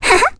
Requina-vox-Laugh.wav